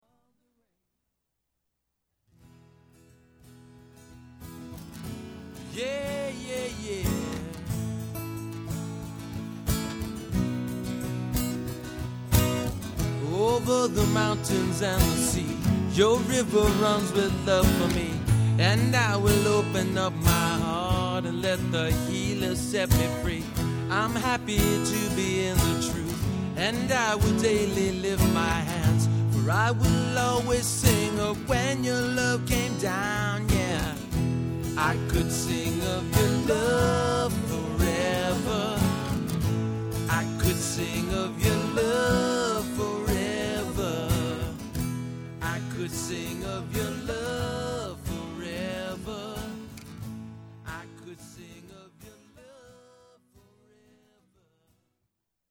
Live Song Samples
Live Demos